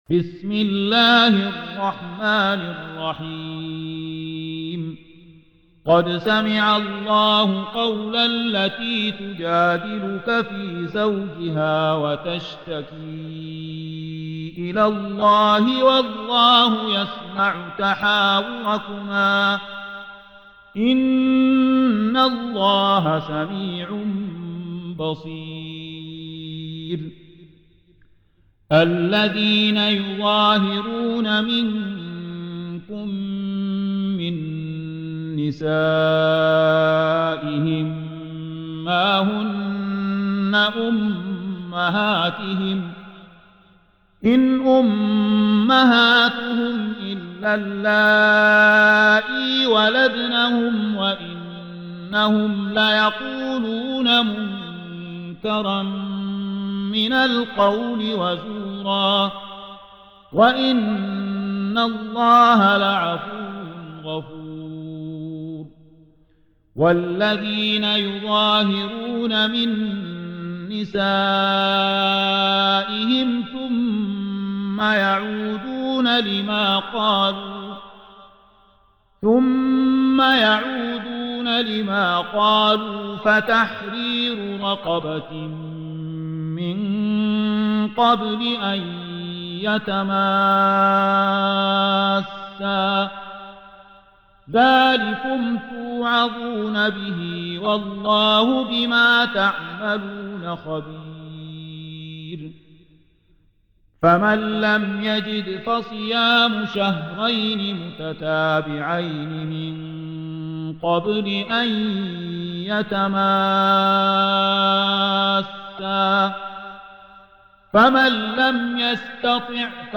58. Surah Al-Muj�dilah سورة المجادلة Audio Quran Tarteel Recitation
Surah Sequence تتابع السورة Download Surah حمّل السورة Reciting Murattalah Audio for 58.